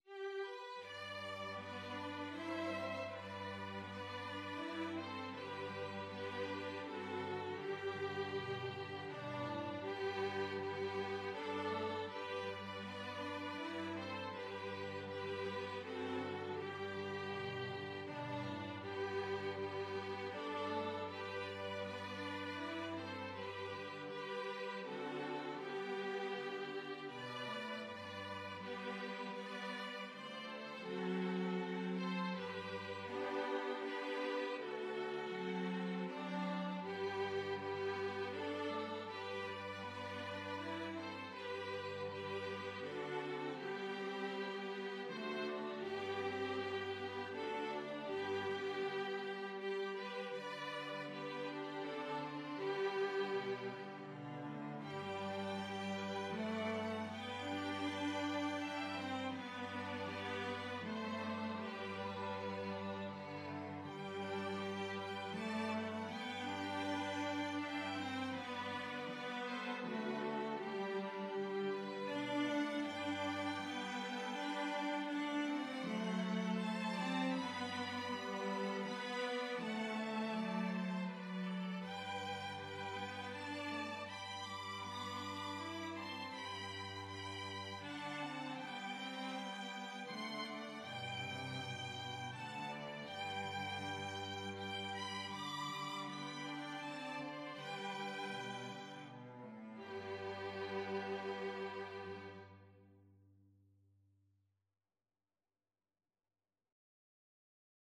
Es Wird Scho Glei Dumpa (Austrian Trad.) Free Sheet music for 2-Violins-2-Cellos